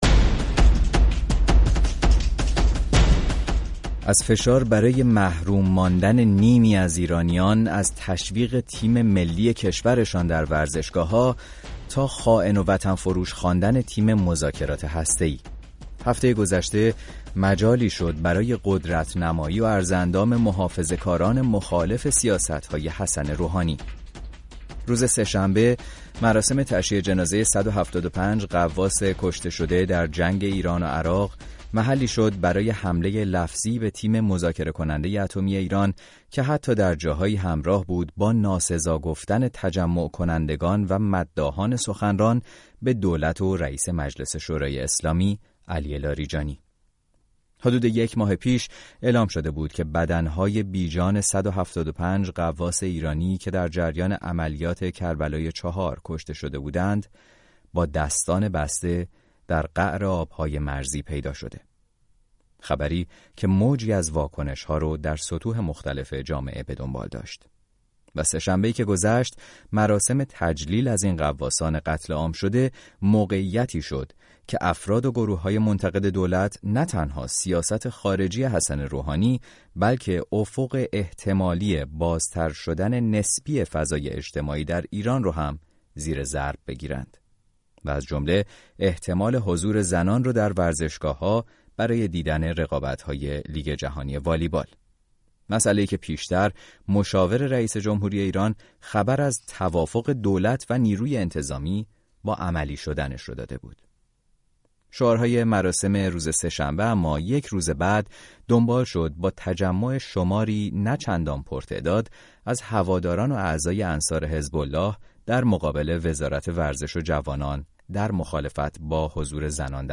تحلیلگر و فعال سیاسی ملی- مذهبی و مخاطبان رادیو فردا